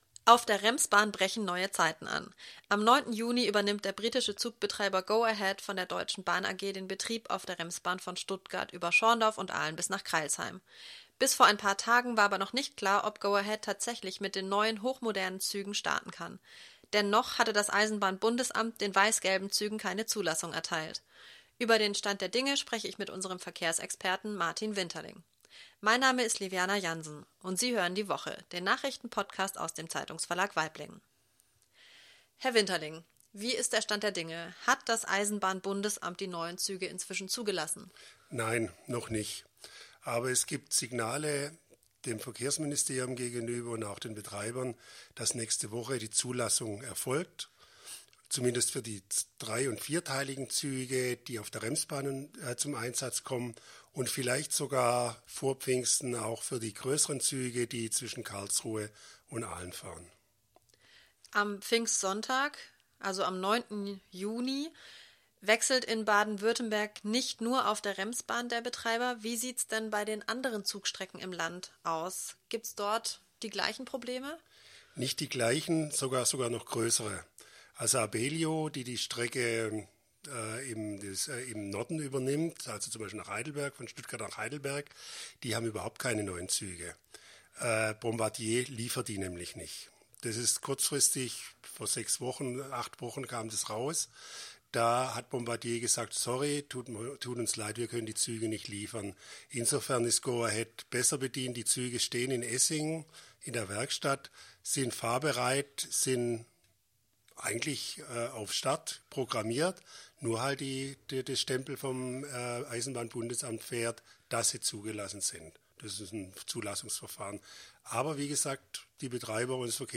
Zu Pfingsten beginnt eine neue Ära auf der Remsbahn von Stuttgart über Schorndorf und Aalen nach Crailsheim. 11 Minuten 9.65 MB Podcast Podcaster Die Woche Die Woche ist der Nachrichten-Podcast aus dem Zeitungsverlag Waiblingen.